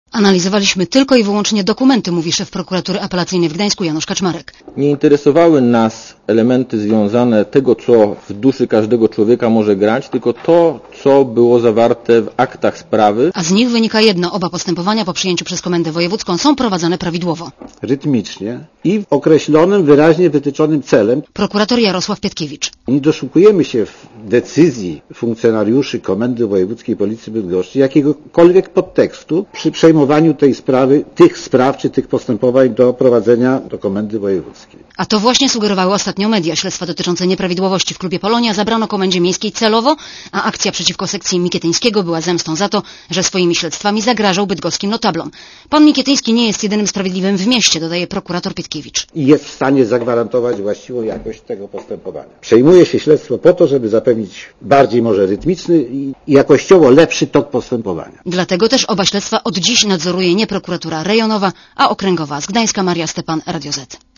Posłuchaj relacji reporterki Radia Zet (249 KB)